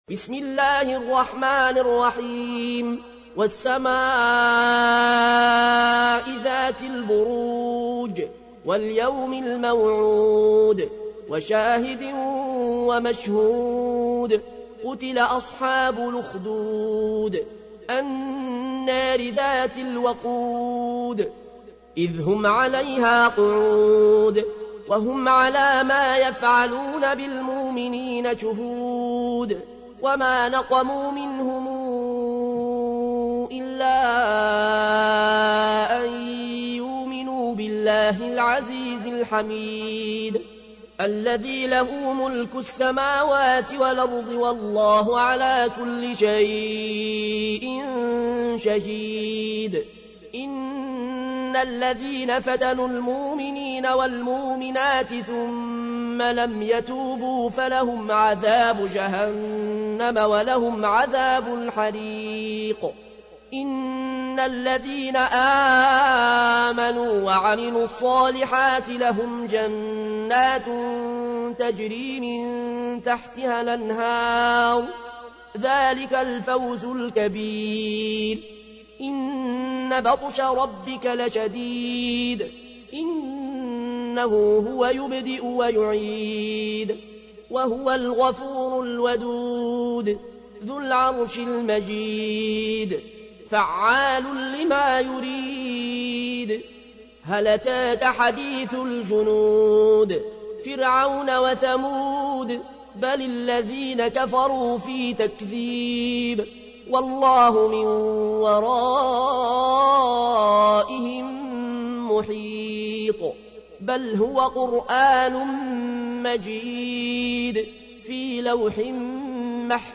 تحميل : 85. سورة البروج / القارئ العيون الكوشي / القرآن الكريم / موقع يا حسين